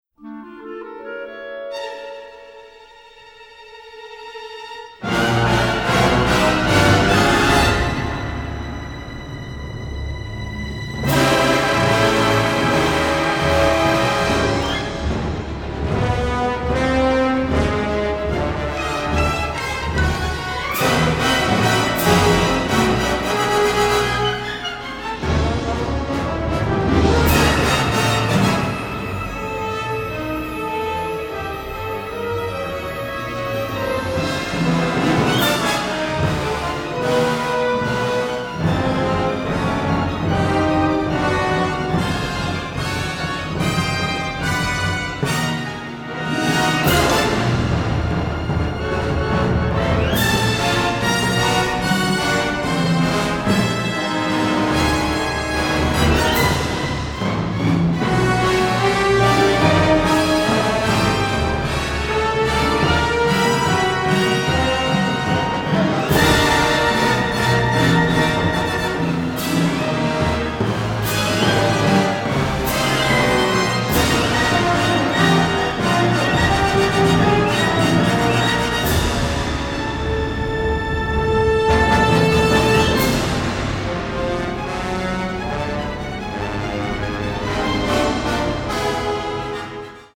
powerhouse, original orchestral material